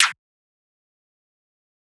SU_Snare (Moon Rocks).wav